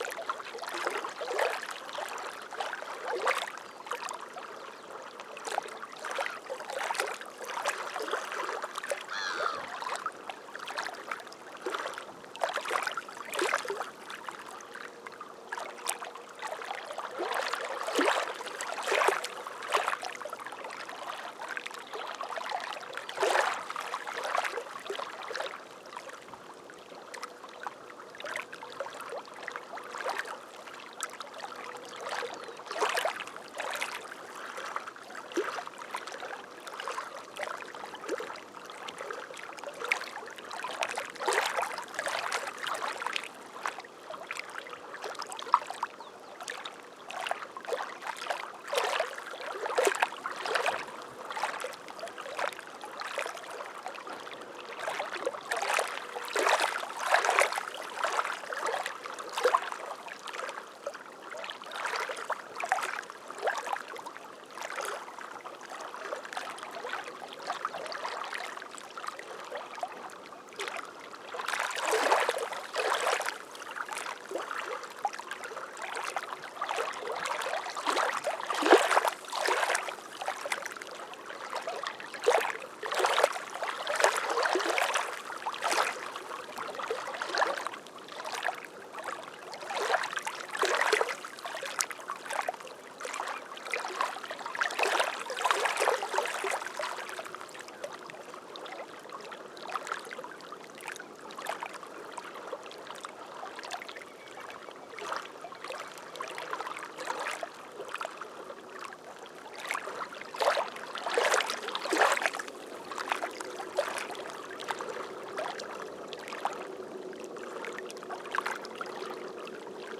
Bajada pescadores 17 hs. 27 de Agosto 2021
esf-desvio-arijon-rio-coronda.mp3